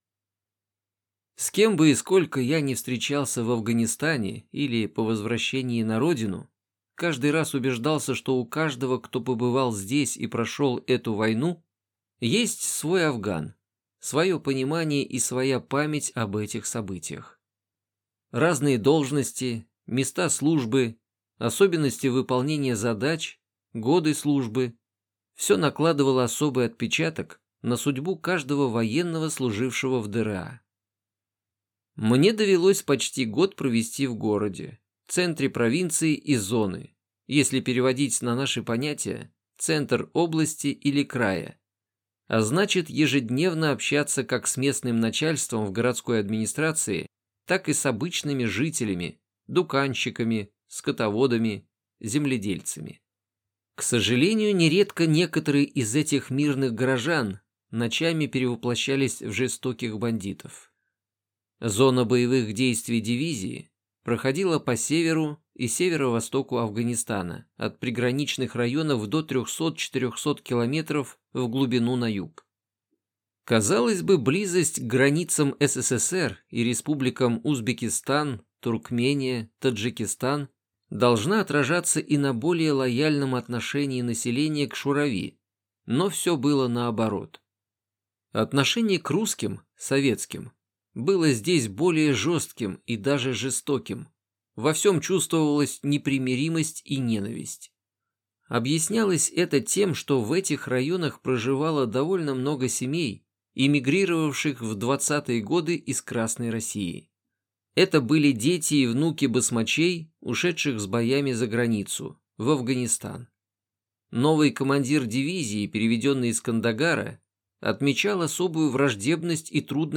Аудиокнига Мой немой Афган | Библиотека аудиокниг